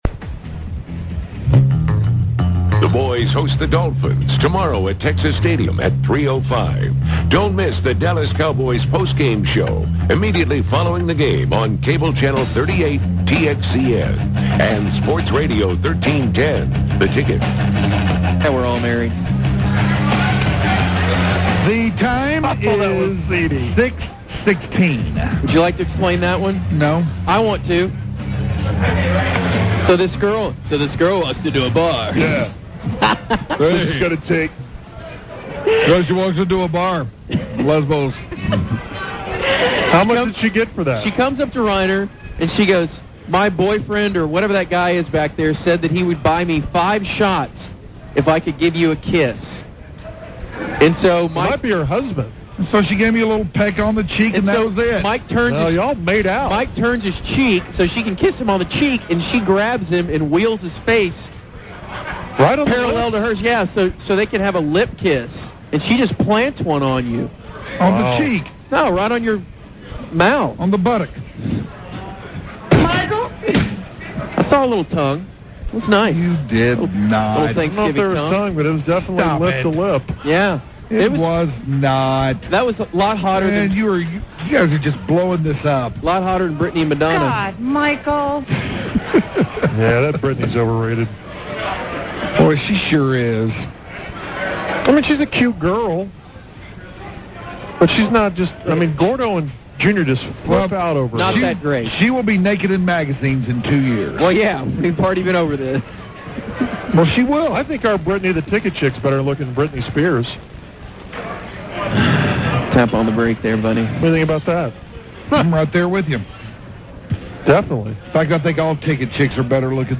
farts on the phone